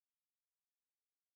Spawn Start Sound Effect
spawn-start.mp3